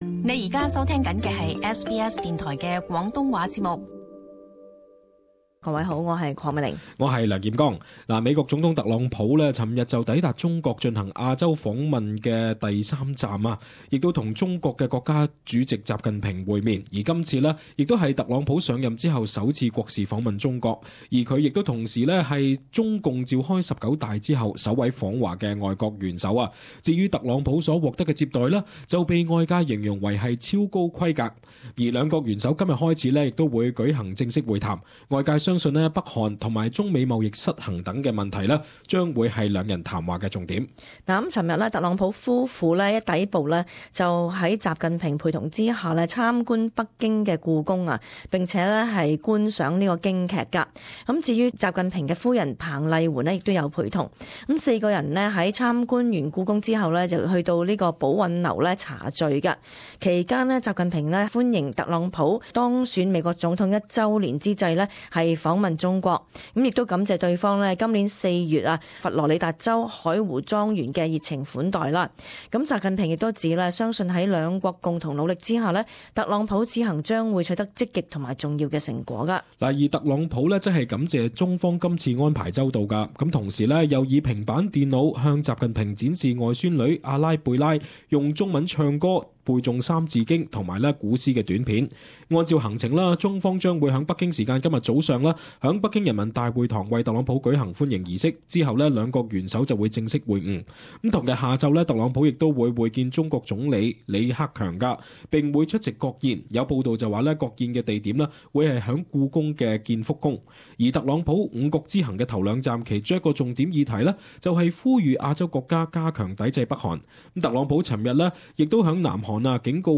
【时事报导】习近平以超高规格接待特朗普